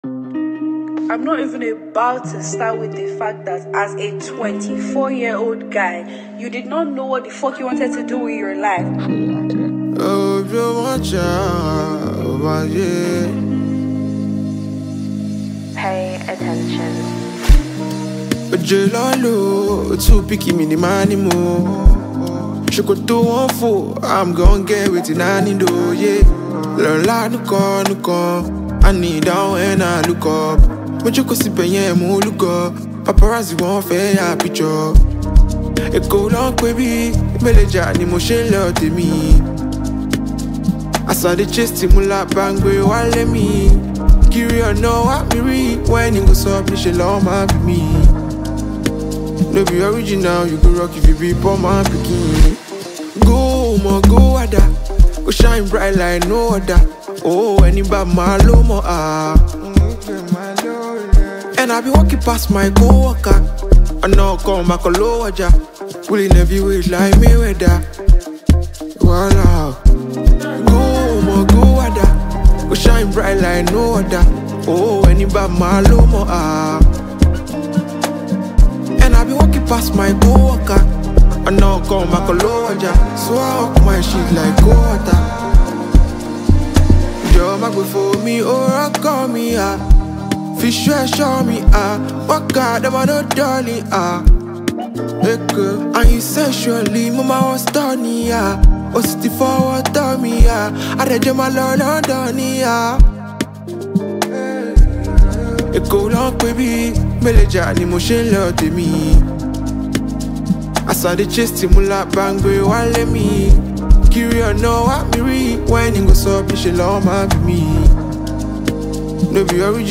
Afrobeats
acclaimed for his emotive vocal delivery and soulful fusions
a vibrant track